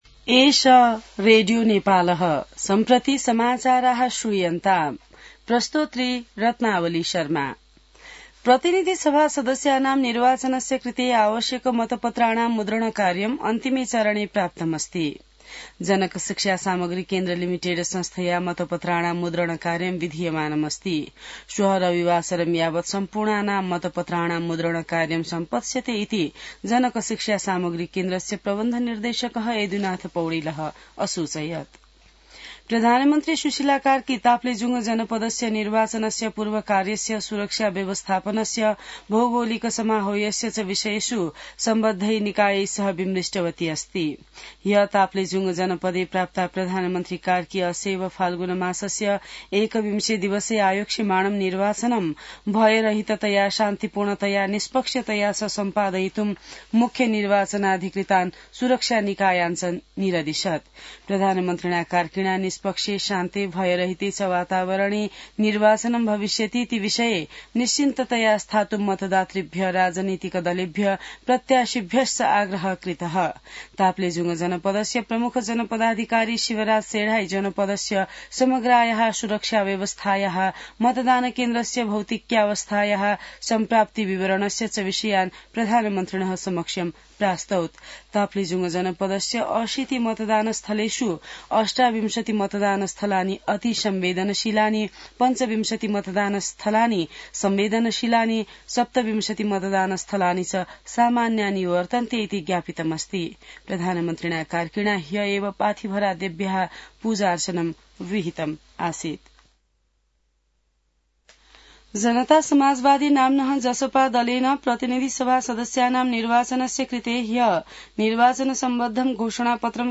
संस्कृत समाचार : २ फागुन , २०८२